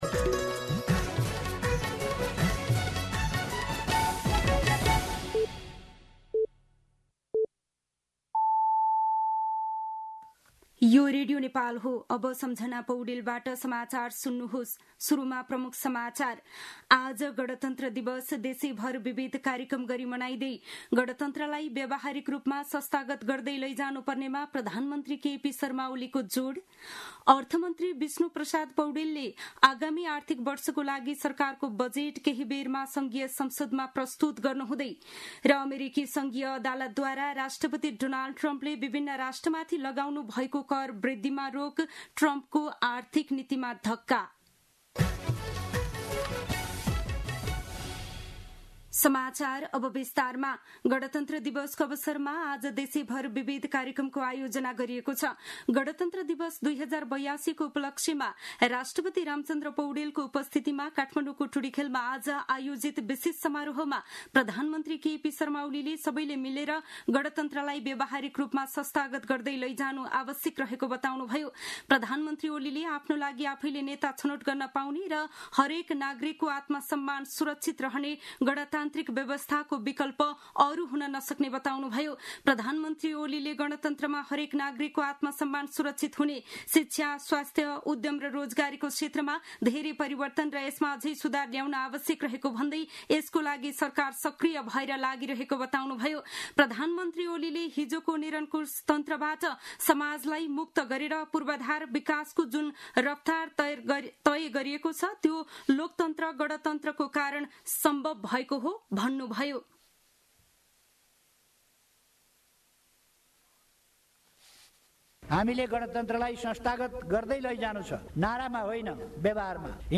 दिउँसो ३ बजेको नेपाली समाचार : १५ जेठ , २०८२